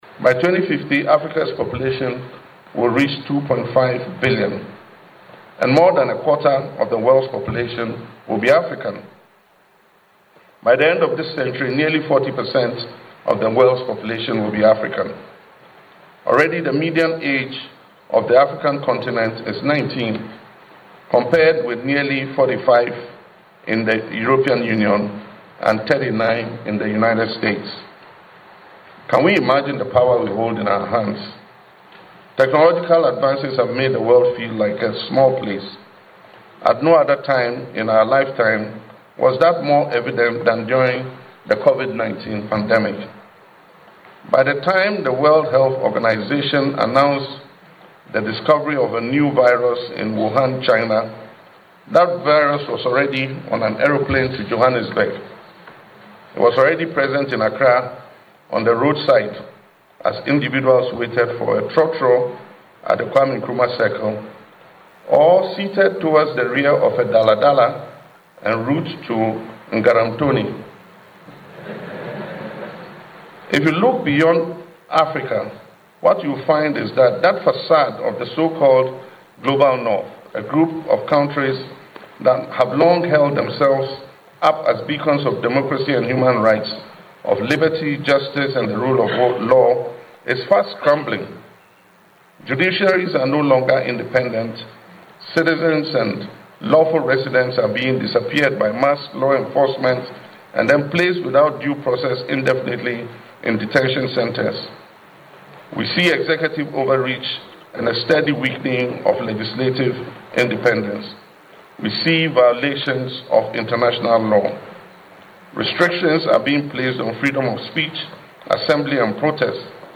Speaking at the opening of the 2026 Judicial Year of the African Court on Human and Peoples’ Rights, President Mahama highlighted projections that by 2050, Africa’s population will rise to approximately 2.5 billion people accounting for more than a quarter of the world’s population.